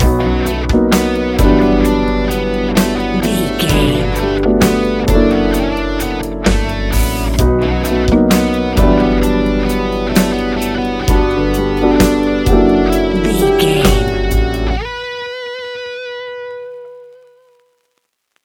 Ionian/Major
B♭
laid back
Lounge
sparse
new age
chilled electronica
ambient